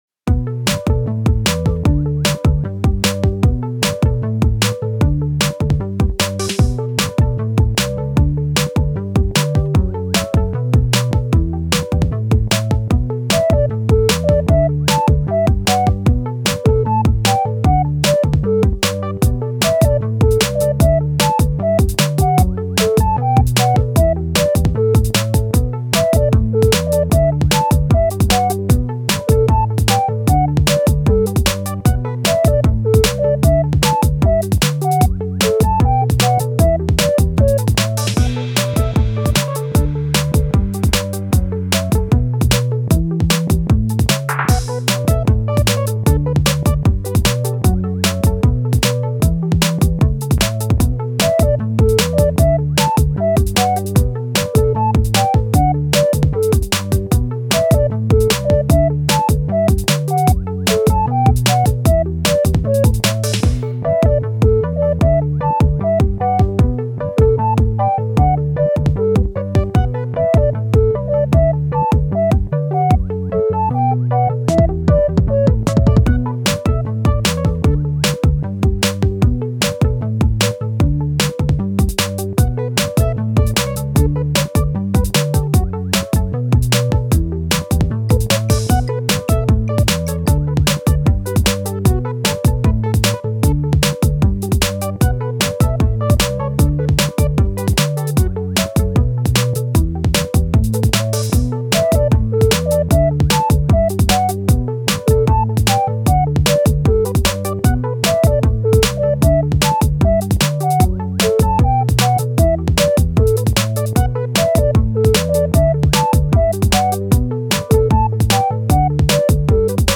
エレクトロ オルタナティヴ フリーBGM